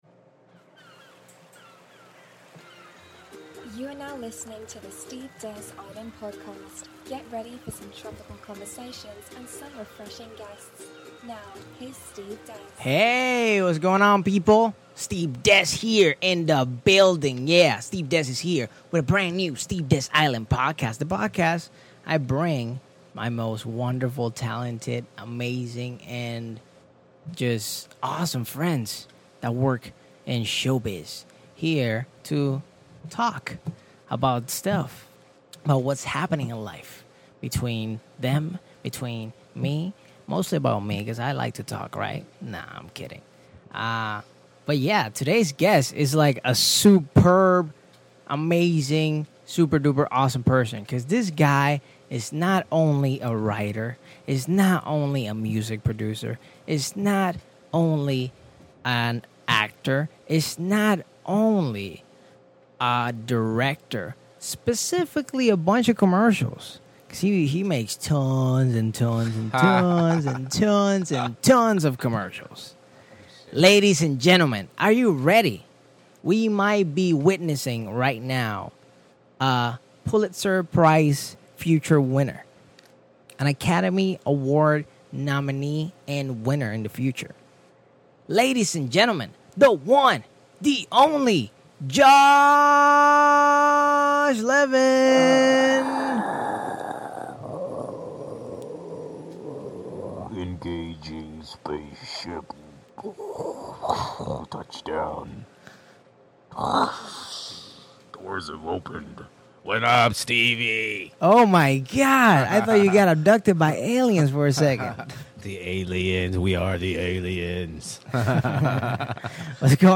We go balls out on an improv scene, we also talk about why he thinks Jordan is the best basketball player and the future of the business.